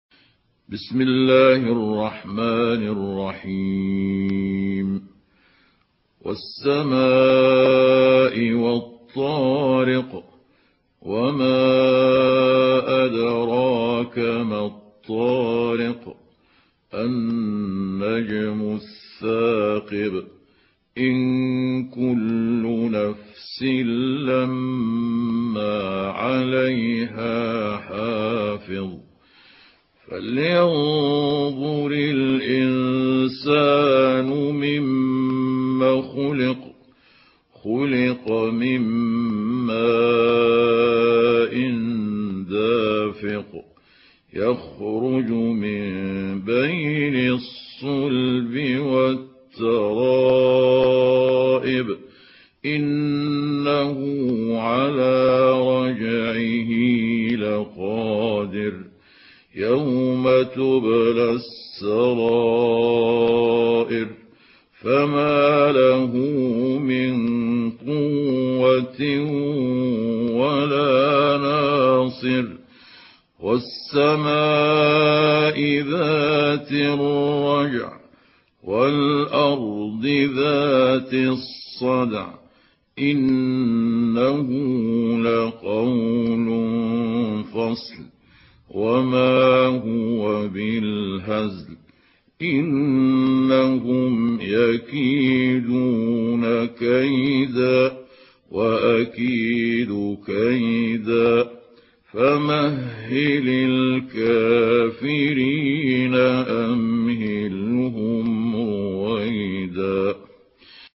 Surah At-Tariq MP3 by Mahmoud Abdul Hakam in Hafs An Asim narration.
Murattal